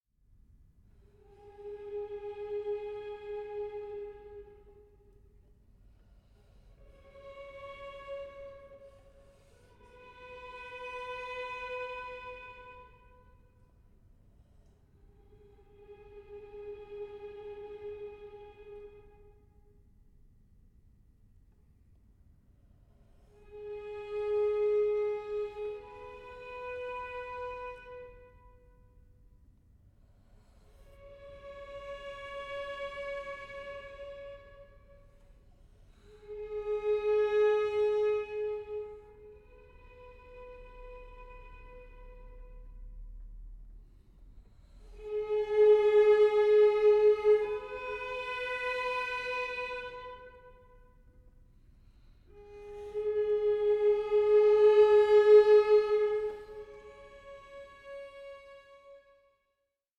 96/24 Stereo  14,99 Select
String Quartet No. 11 in D Minor, Op. 9, No. 4